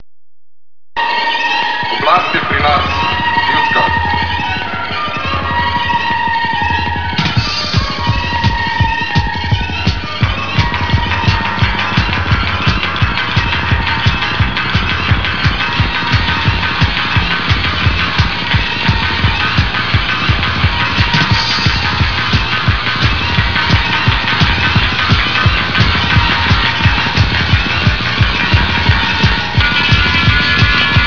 industrial music